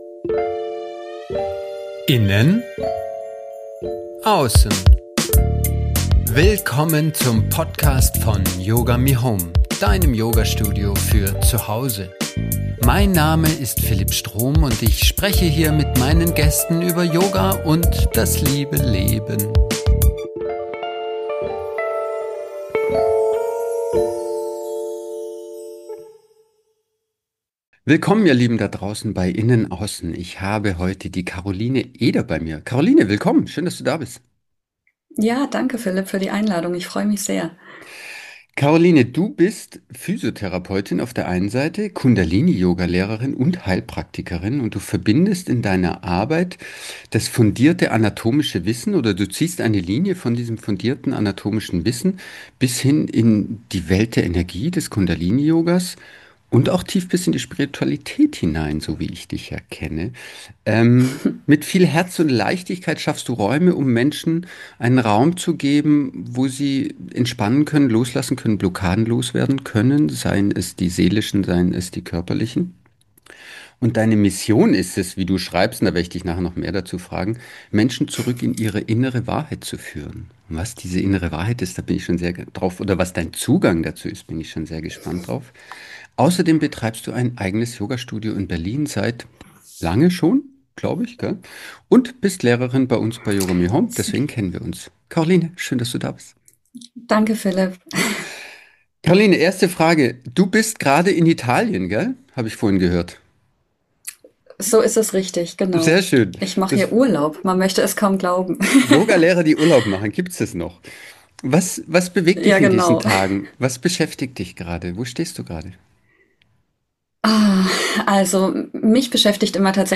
Ein Gespräch voller Tiefe, Authentizität und Lebensnähe.